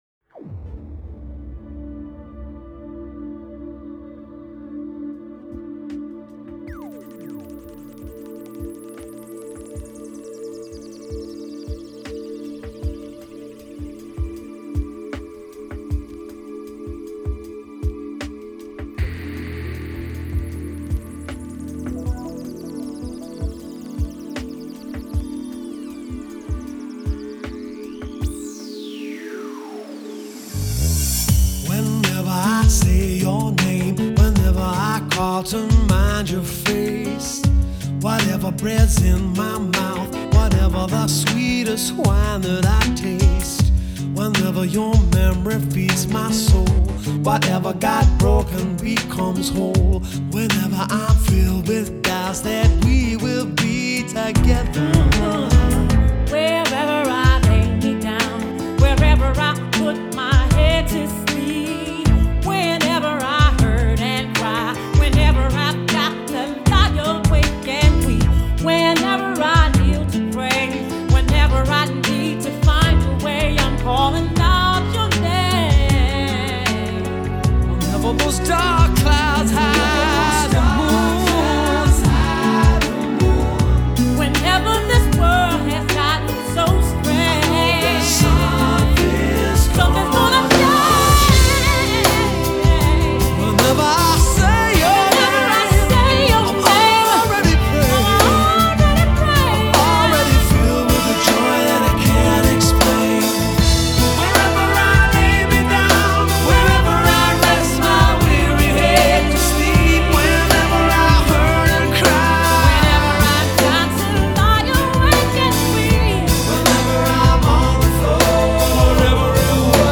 Genre: New Wave